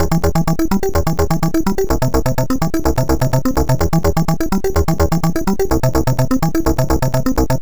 Wild At Arp F 126.wav